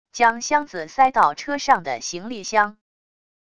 将箱子塞到车上的行李箱wav下载